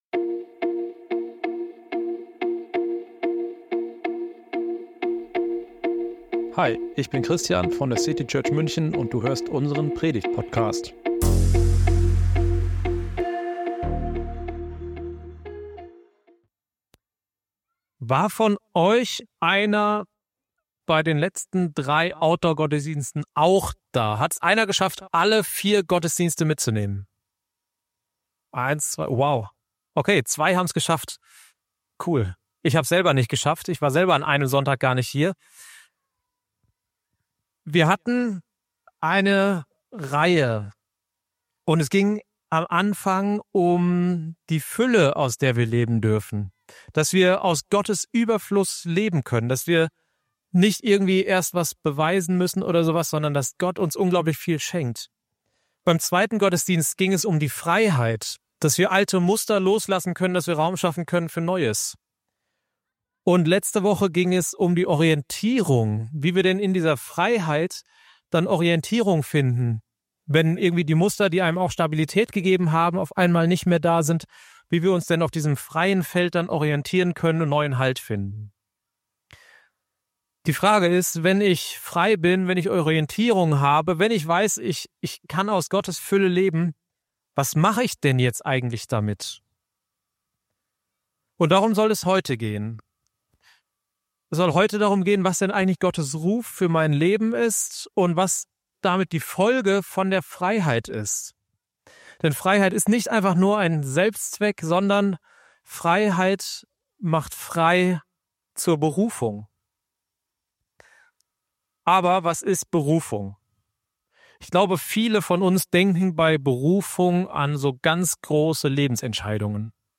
In dieser Predigt geht es um Berufung – nicht als großer Plan, sondern als Einladung, Schritt für Schritt mit Gott zu gehen. Lass dich ermutigen, das in dir zu entdecken, was wachsen will.